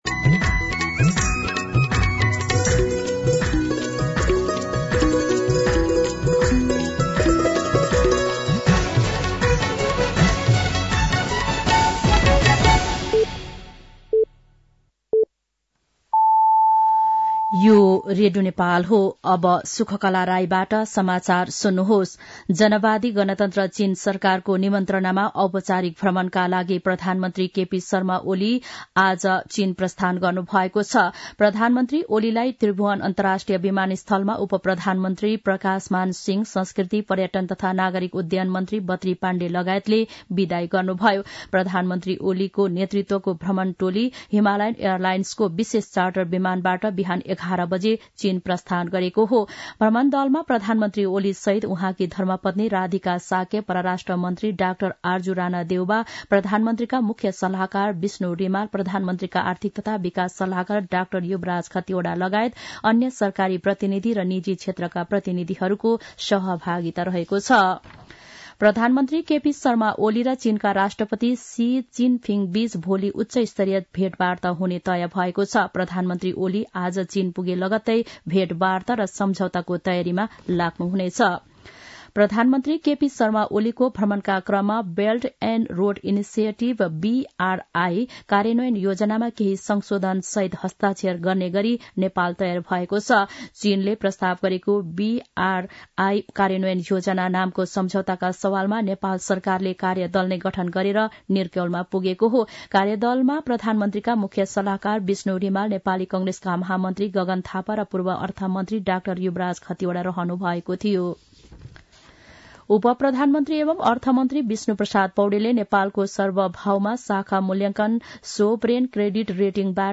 An online outlet of Nepal's national radio broadcaster
साँझ ५ बजेको नेपाली समाचार : १८ मंसिर , २०८१
5-PM-Nepali-News-8-17.mp3